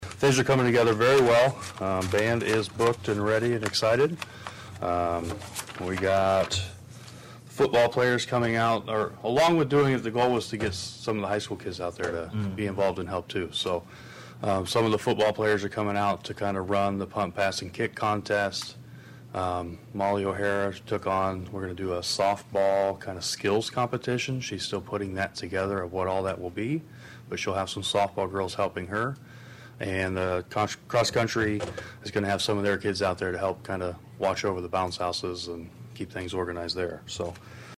Atlantic Police Chief Devan Hogue appearing at the Atlantic City Council meeting on Wednesday, says the event is called “Bash at the Ballfields” at the Little Complex from 5:00 p.m. to 10:00 p.m.